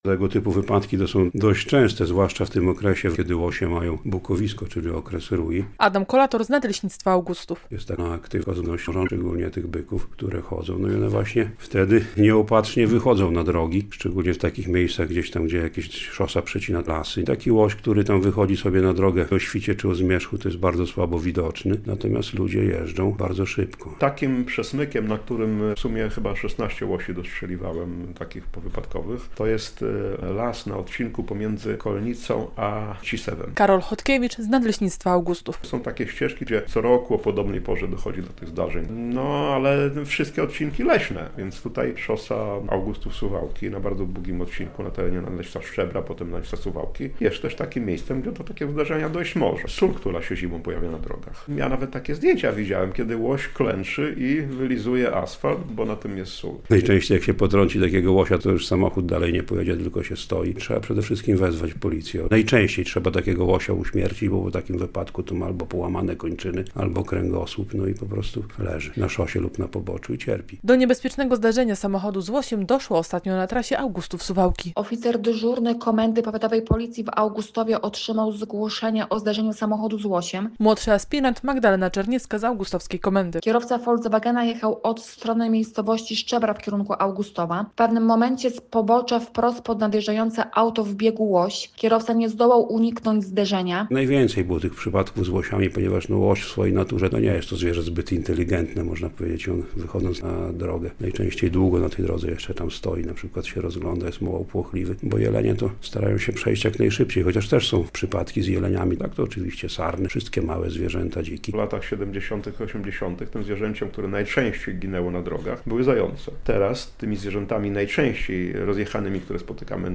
Jesienią rośnie liczba wypadków z udziałem zwierząt - relacja